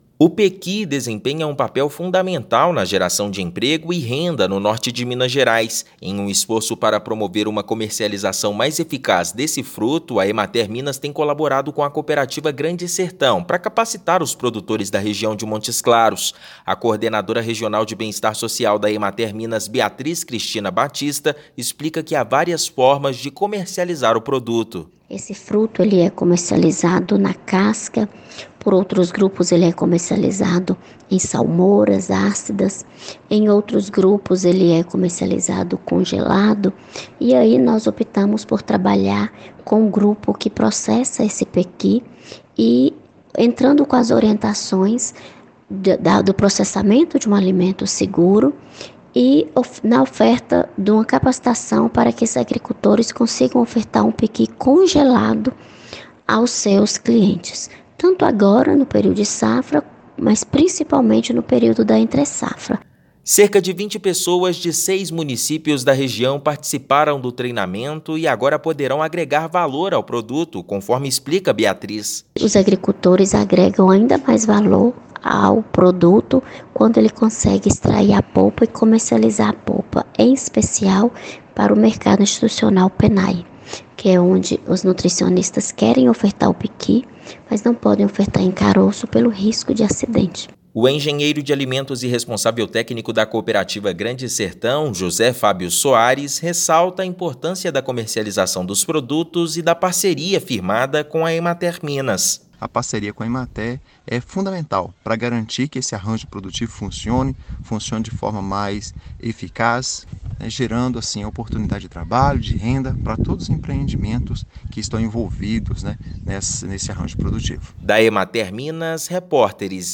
[RÁDIO] Pequi movimenta a economia no Norte de Minas e ganha impulso com capacitação de produtores
Agricultores que participam da qualificação aprendem a agregar valor ao produto. Ouça matéria de rádio.